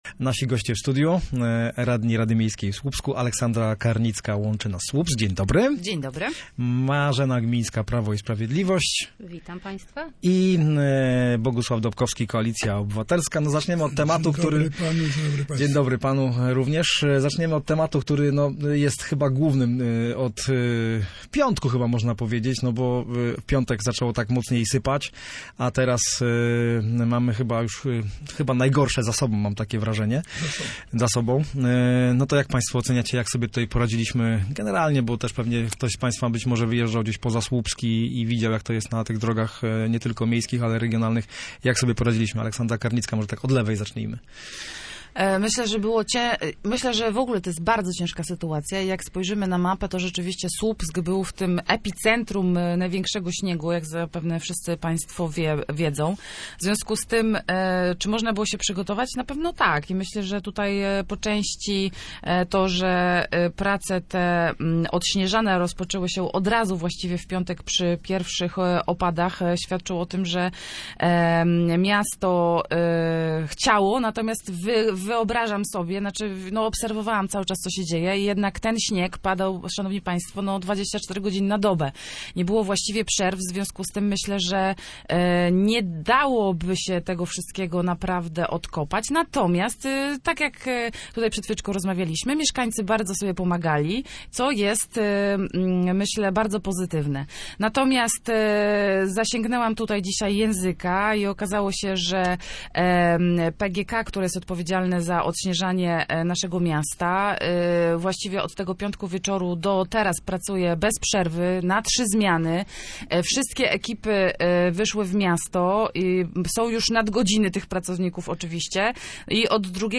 Słupscy radni dyskutowali o ataku zimy i wyzwaniach budżetu miasta na rok 2026.
Zaproszenie do rozmowy w miejskim programie Radia Gdańsk w Słupsku przyjęli Aleksandra Karnicka z klubu Łączy nas Słupsk, Marzena Gmińska z Prawa i Sprawiedliwości oraz Bogusław Dobkowski z Koalicji Obywatelskiej.